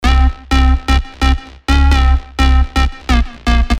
描述：为你的EDM轨道提供House、Techno或OldSkool主合成器
Tag: 140 bpm Dance Loops Synth Loops 590.74 KB wav Key : Unknown